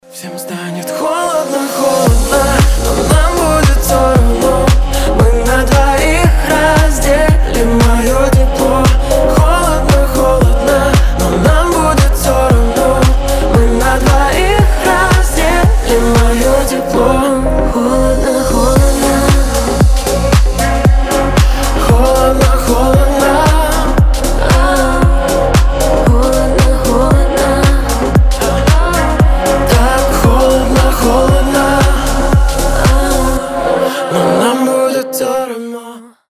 • Качество: 320, Stereo
дуэт